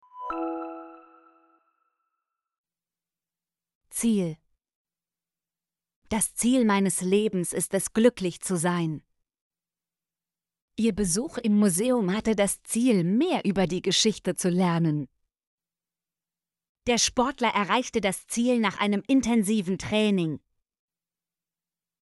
ziel - Example Sentences & Pronunciation, German Frequency List